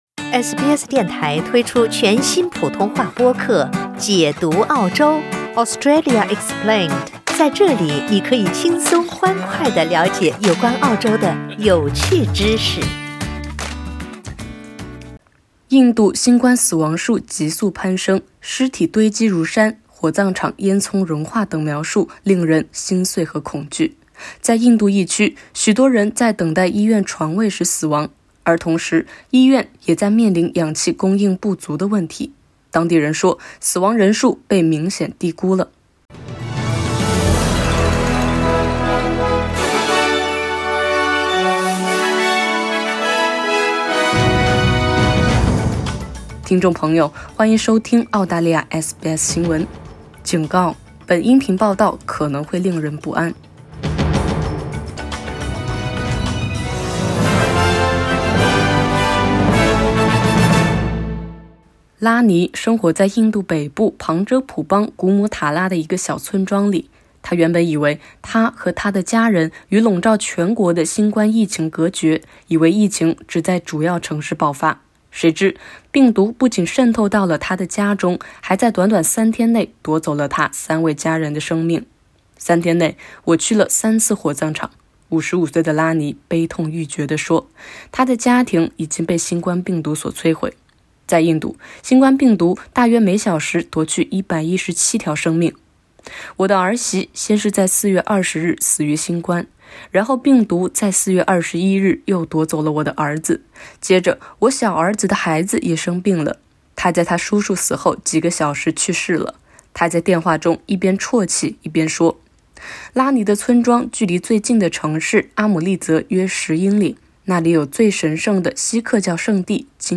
（点击上图收听报道。警告：本音频报道可能会令人不安。）